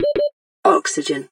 OxygenBeep.ogg